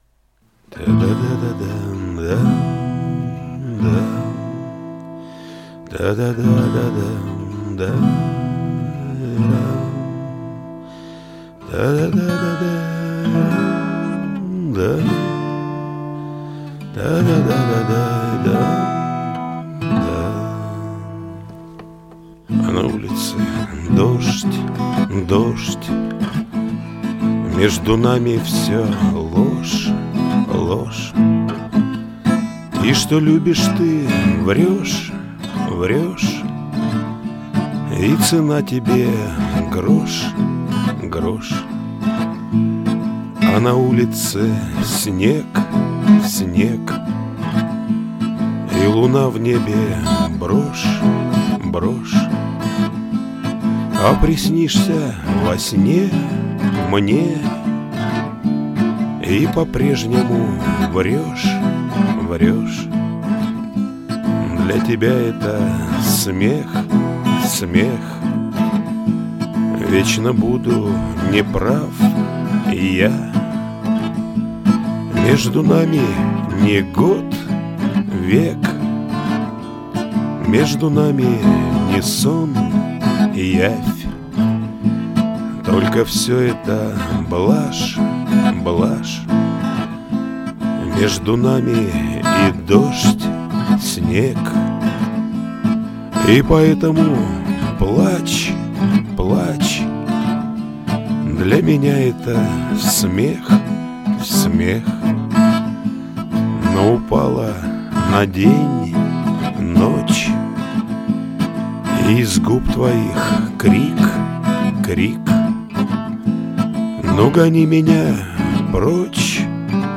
К примеру спеть просто на диктофон в телефоне.. Я вот попробовал спеть аккомпанируя себе на гитаре.., а фига ли?